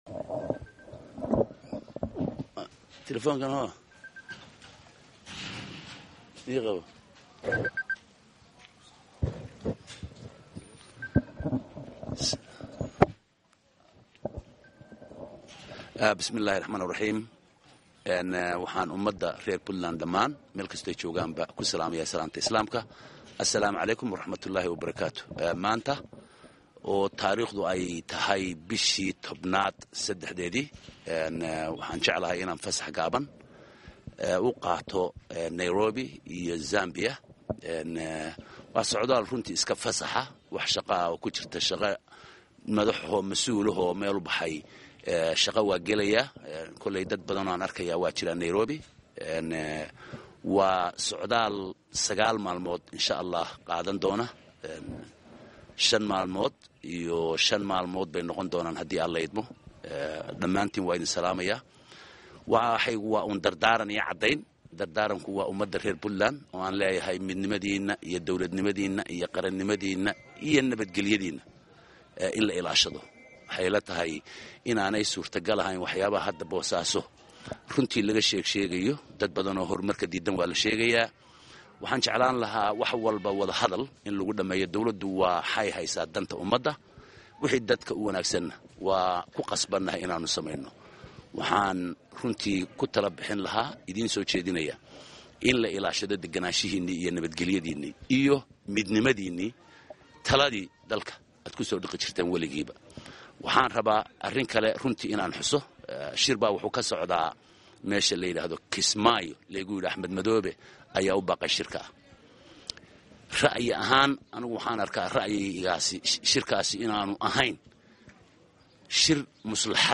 Madaxweyne ku xigeenka Puntland oo saxaafada la hadley ayaa sheegay in wax dan ah ku jirin tagida Kismaayo isla markaana ay muhiim tahay in madaxdu xaliyaan khilaafyada dhexdooda ah.